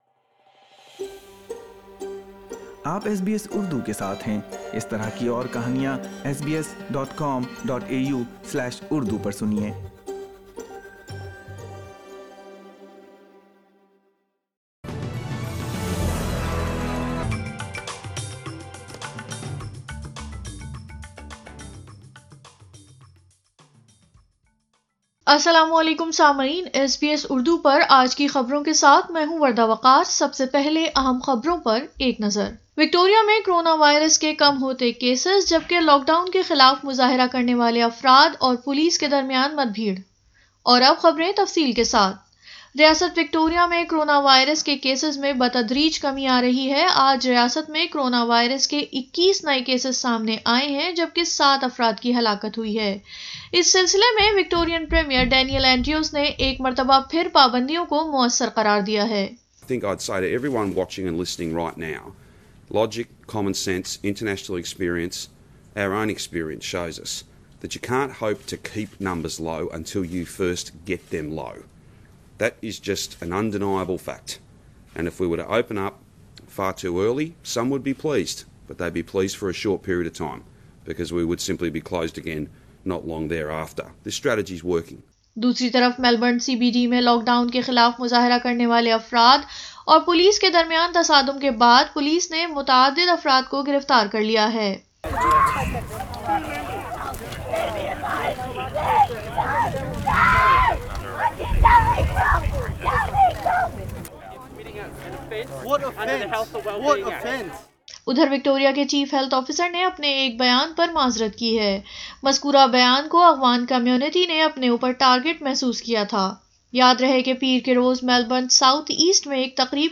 اردو خبریں 19 ستمبر 2020